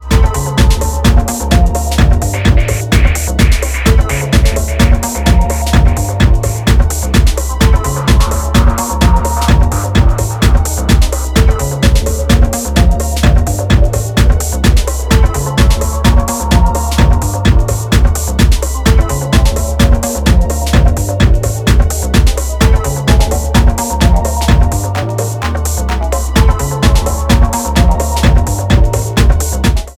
Techno Ambient